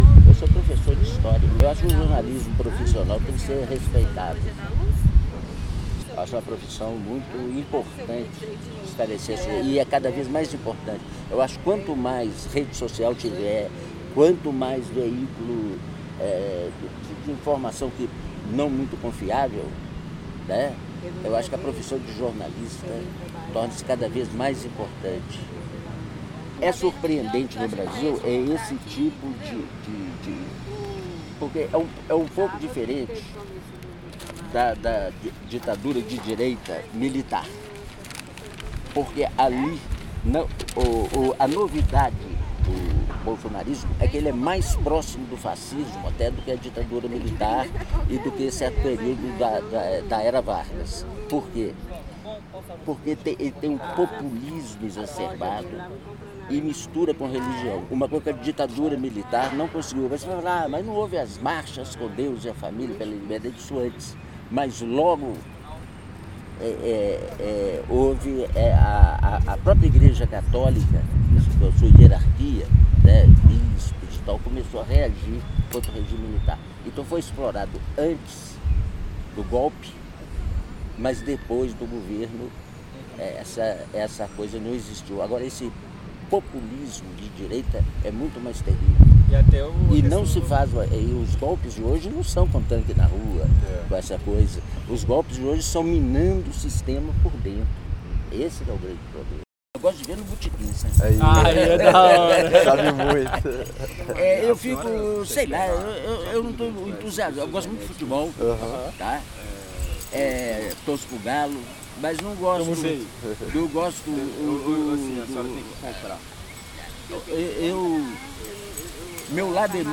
Depoimento